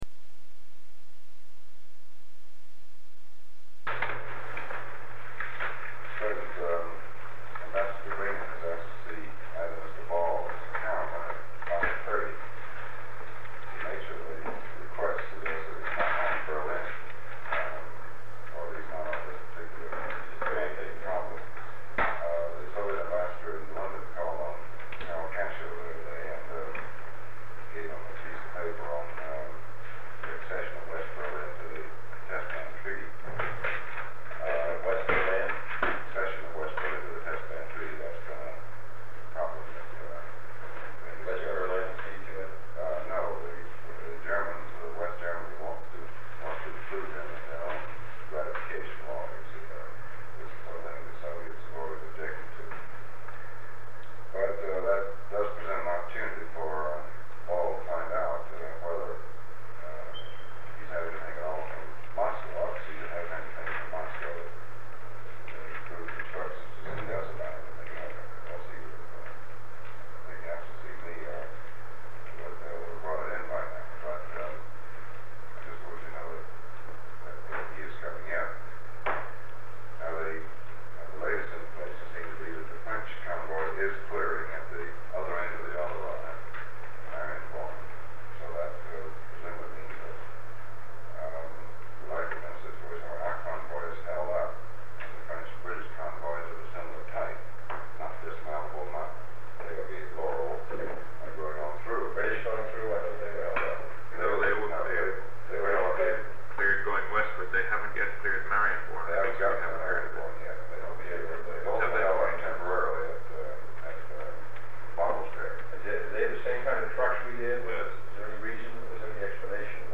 Sound recording of a meeting about Berlin, Germany, held on November 5, 1963, between President John F. Kennedy, Secretary of State Dean Rusk, Director of the Central Intelligence Agency (CIA) John McCone, Special Assistant to the President for National Security Affairs McGeorge Bundy, Secretary of Defense Robert S. McNamara, United States Ambassador to the Soviet Union Llewellyn Thompson, and President Kennedy’s Personal Representative in Berlin General Lucius Clay.
There is further discussion of the possible situation that may arise in Berlin and the possible U.S. response and contingency plans. One segment of the recording totaling 1 minute has been removed in accordance with Section 3.4 (b) (1), (3) of Executive Order 13526.